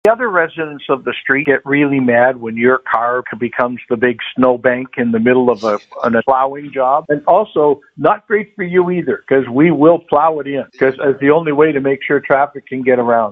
Preston cautioned, if you insist on parking on the street – especially during a heavy snowfall – expect to be digging a lot of the white stuff away from your car.
nov-14-joe-preston-overnight-parking-on-air-1.mp3